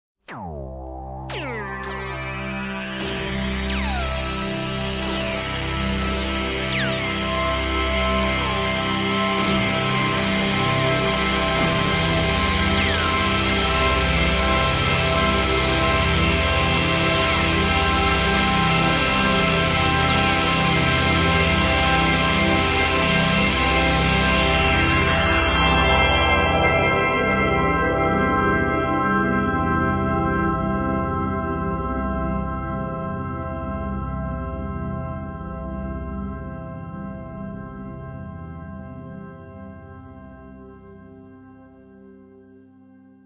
Not when you have you have a Minimoog and a 16 track recorder... ;-)